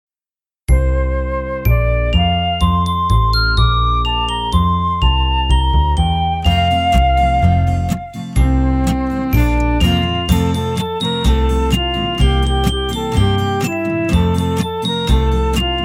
Praise Song for Children